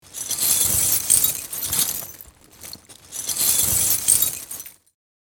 Download Free Glass Sound Effects | Gfx Sounds
Small-glass-debris-shards-movement.mp3